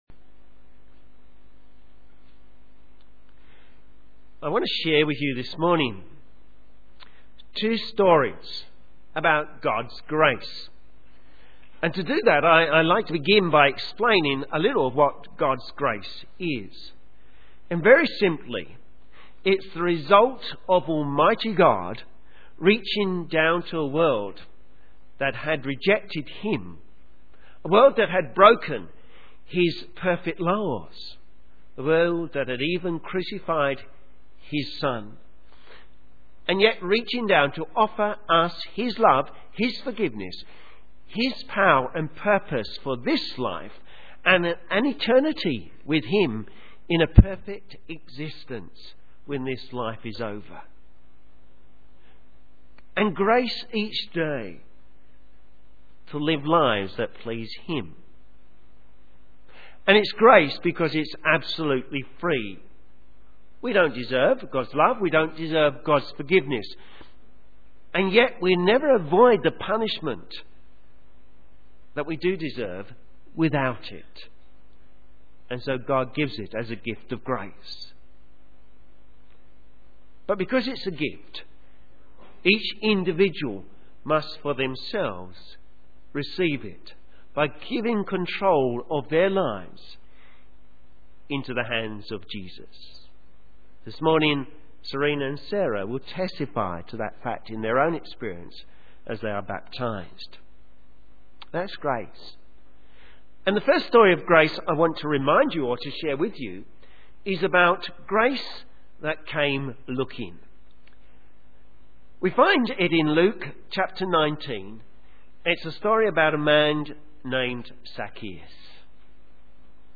Sermon
Baptism Acts 8:26-40 Synopsis A baptismal sermon that looks at 2 stories of Grace. Grace that came looking: Zacchaeus.